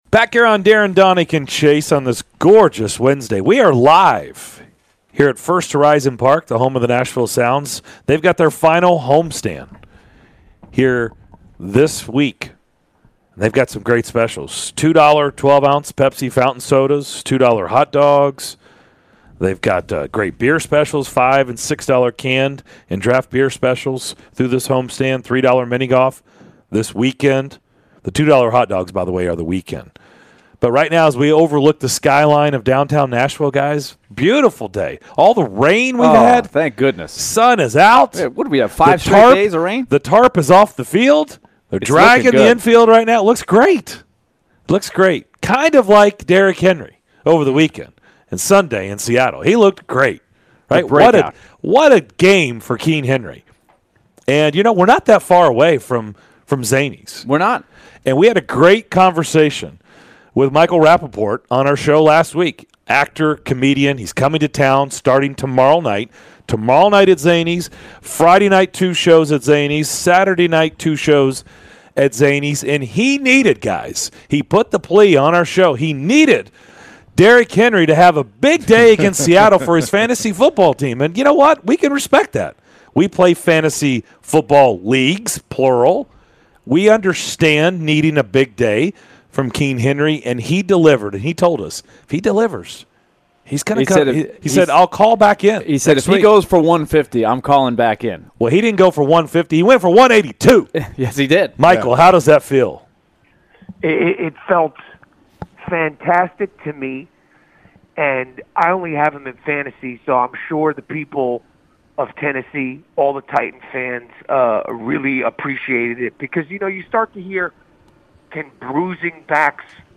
Actor and Comedian Michael Rapaport joined the DDC to take his victory lap after Derrick Henry's big game against the Seahawks!